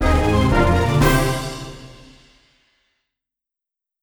ranked_won.wav